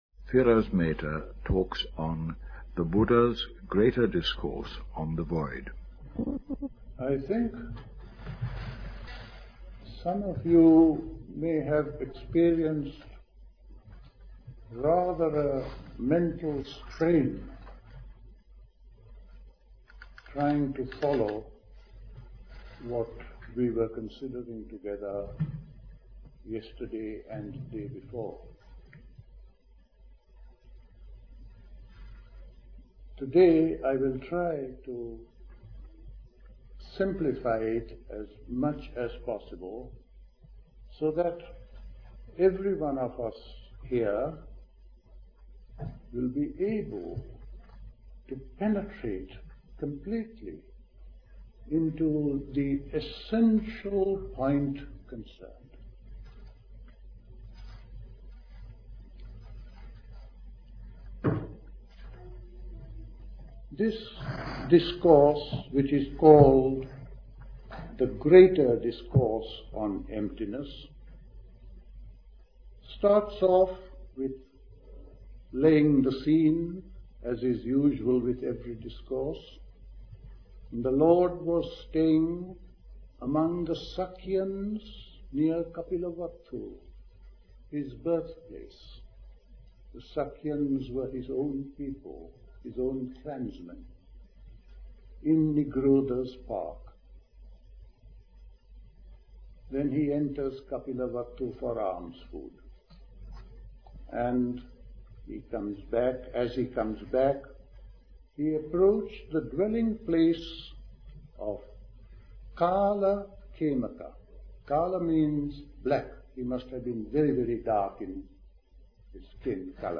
Recorded at the 1975 Buddhist Summer School.